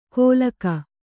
♪ kōlaka